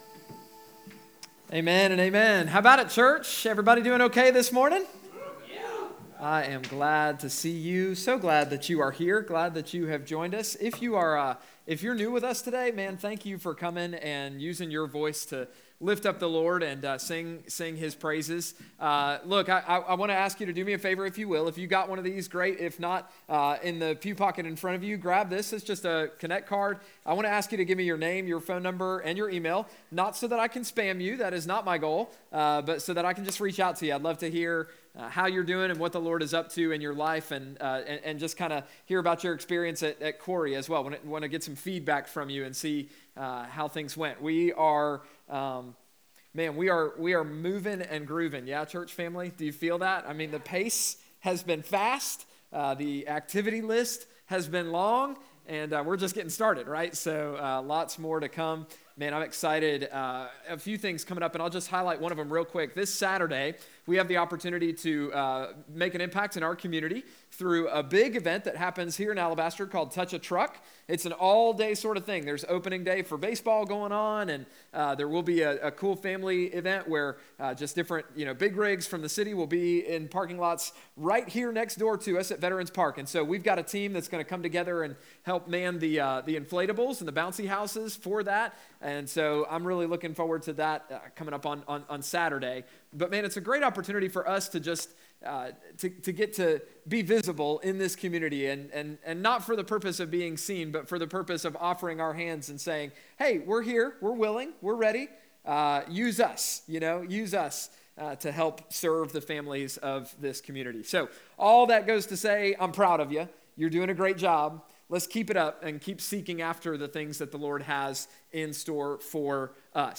Contact Us New Worship Center Connect Ministries Events Watch QC Live Sermons Give 3.30.2025 - The Return of The Lord March 30, 2025